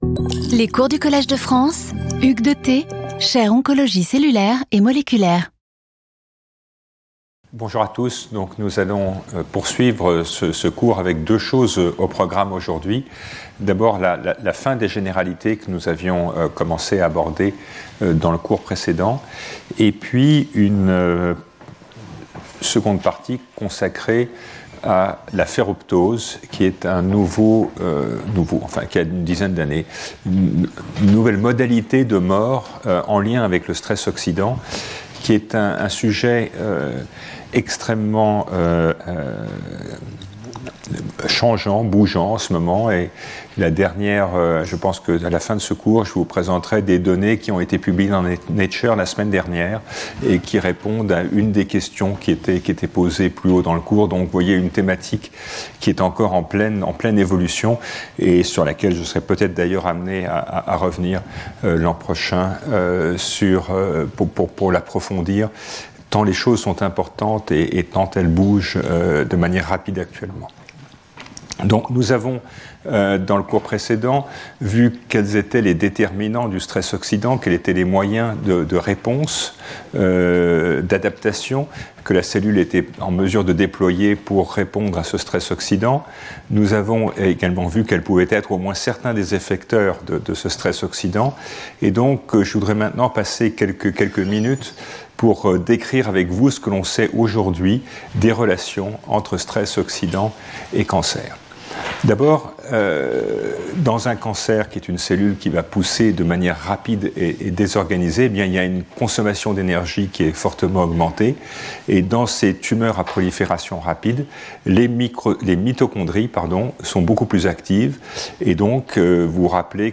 Speaker(s) Hugues de Thé Professor at the Collège de France
Lecture